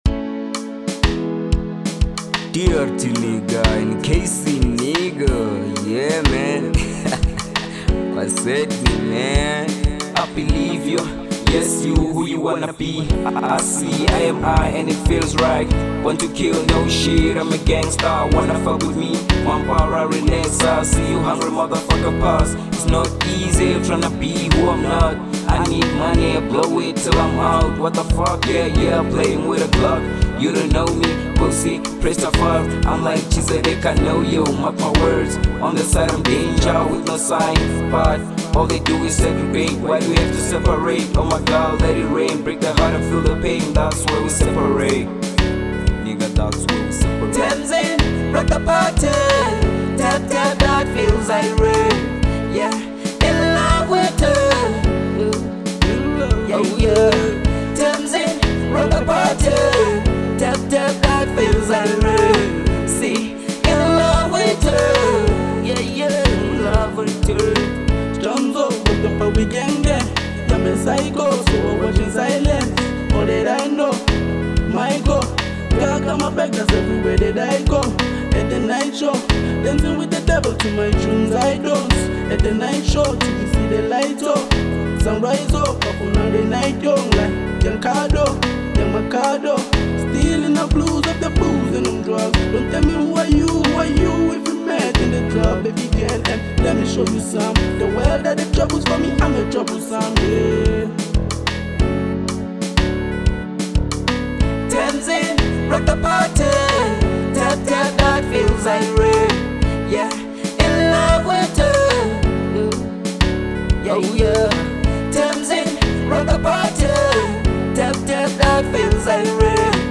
Genre : Hip Hop